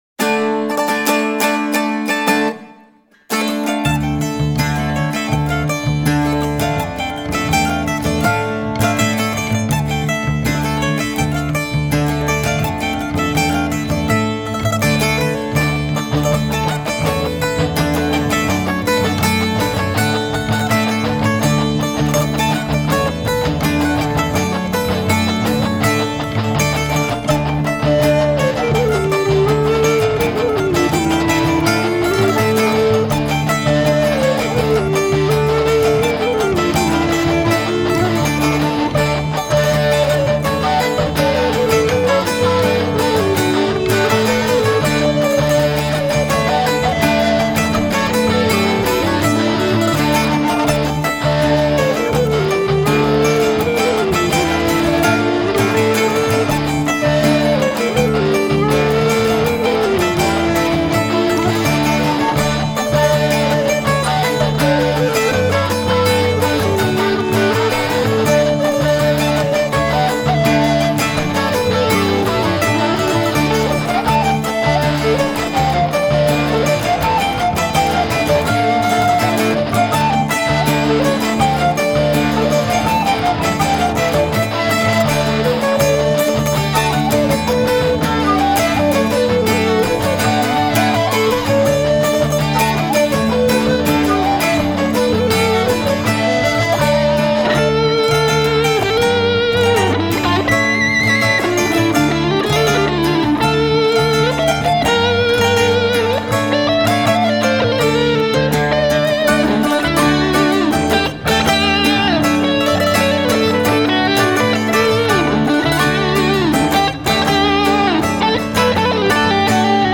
scottisch, rhinländer...
air traditionnel alsacien